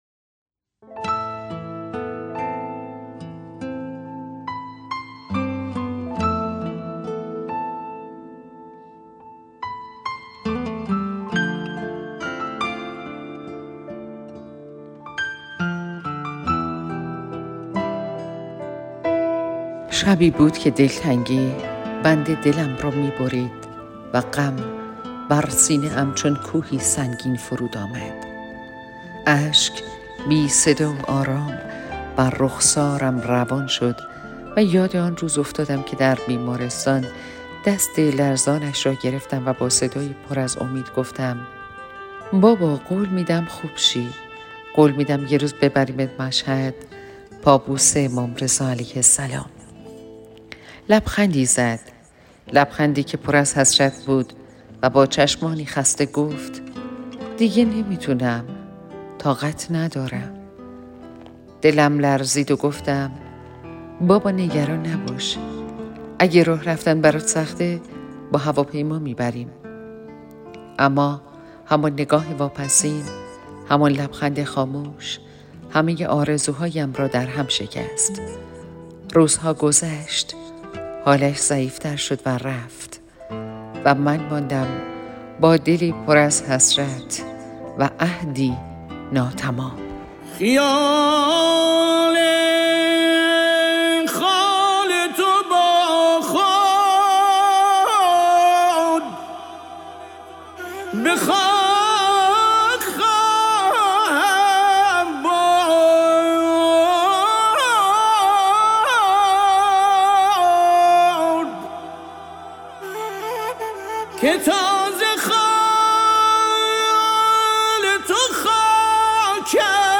پادکستی با موضوع قرار دل‌ها